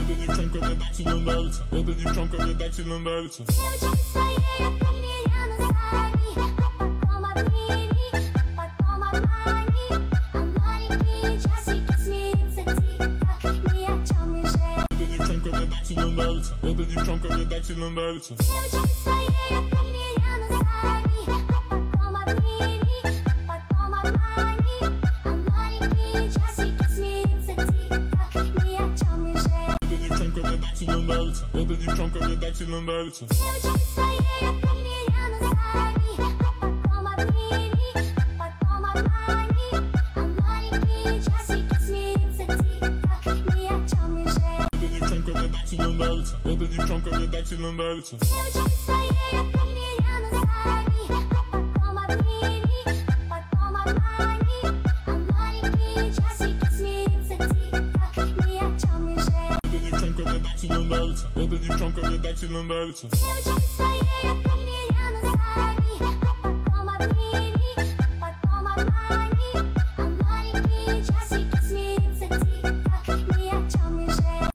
фонк ремикс